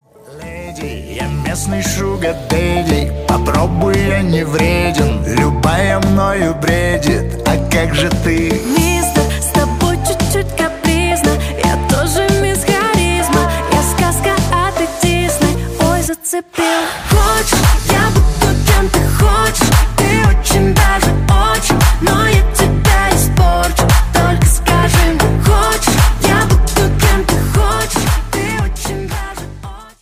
• Качество: 128, Stereo
дуэт